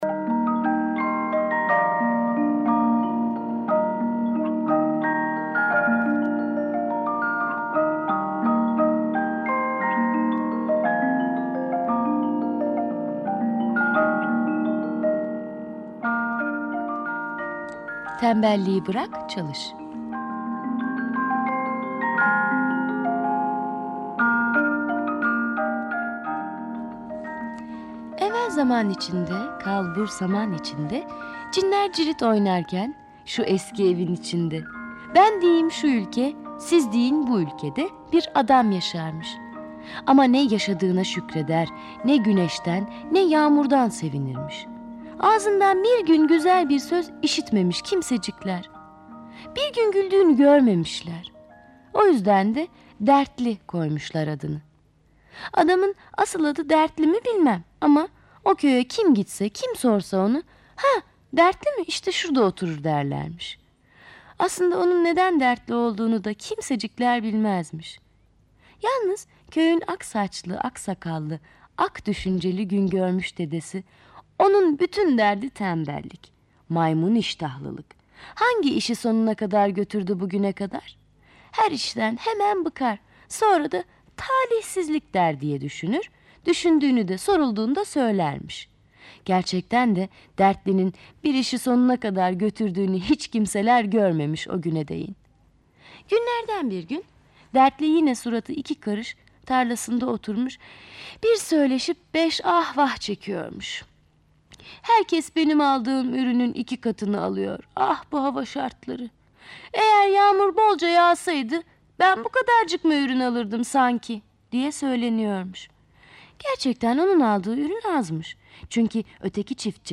Tembelliği bırak çalış sesli masalı, mp3 dinle indir
Kategori Sesli Çocuk Masalları